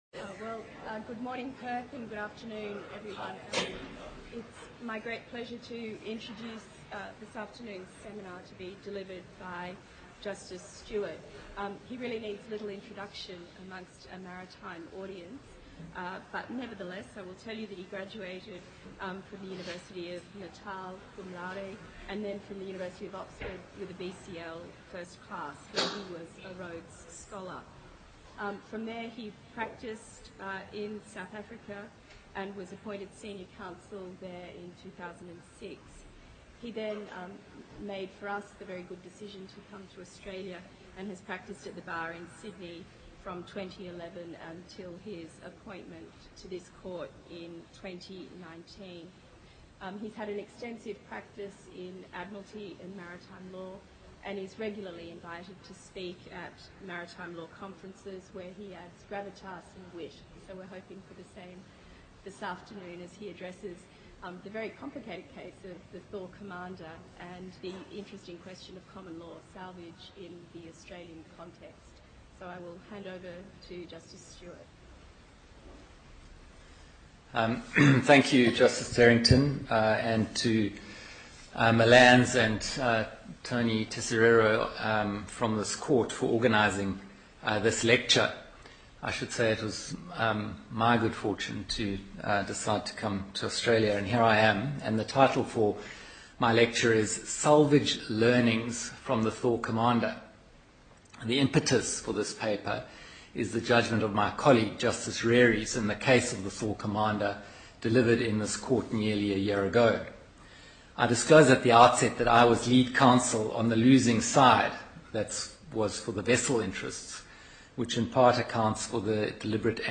MLAANZ / Federal Court of Australia Lunchtime Lecture
* Note: There is some background noise in the audio recording and a gap from 11:40 to 15:30